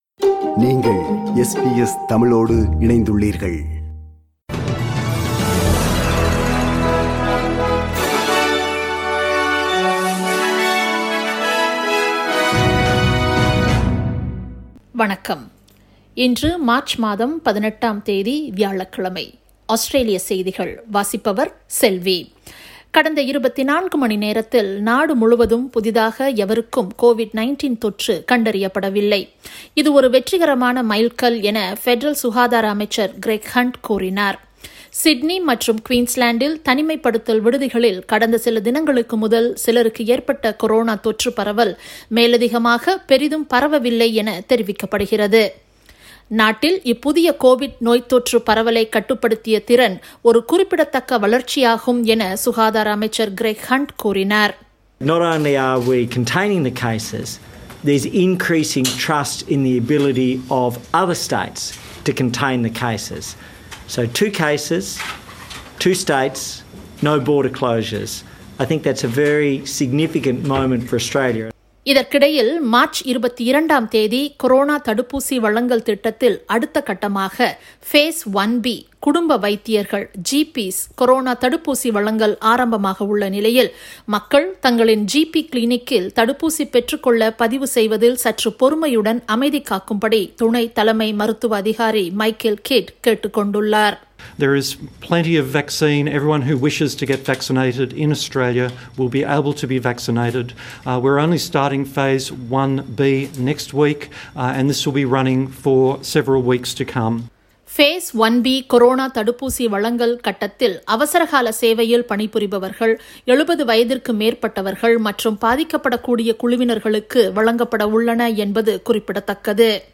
Australian news bulletin for Thursday 18 March 2021.